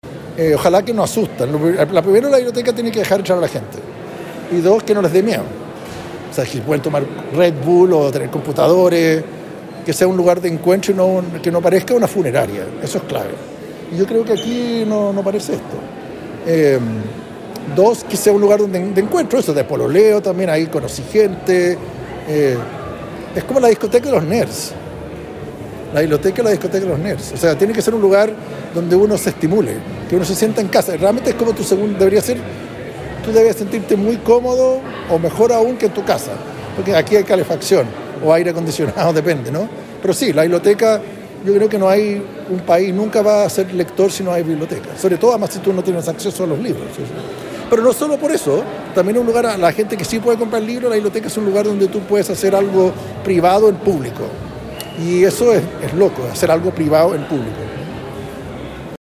El escritor y cineasta participó en un conversatorio abierto a la comunidad en la Biblioteca Central